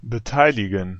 Ääntäminen
Synonyymit teilnehmen Ääntäminen Tuntematon aksentti: IPA: /bəˈtaɪ̯lɪɡən/ Haettu sana löytyi näillä lähdekielillä: saksa Käännöksiä ei löytynyt valitulle kohdekielelle.